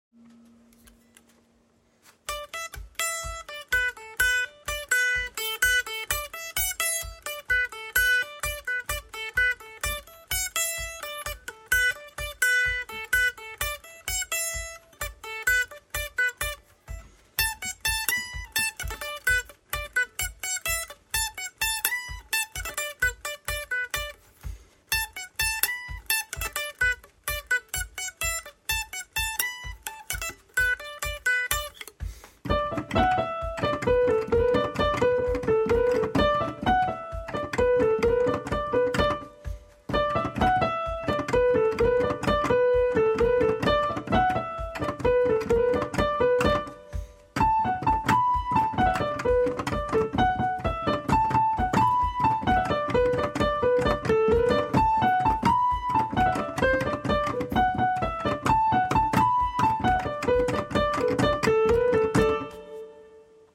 I'd just gotten my new 73-key piano for gigs, and was excited to play it a lot.